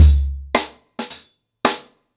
HarlemBrother-110BPM.25.wav